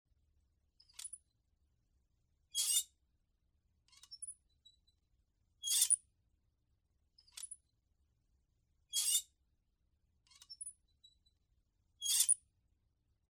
Звук раскачивающегося фонаря от ветра Скрипы и хрусты
Звук монотонного поскрипывания ручки винтажного фонаря. Ручка в виде петли из проволоки.
monotonnoe-poskripyvanie-metallicheskoj-ruchki-vintazhnogo-fonarja.mp3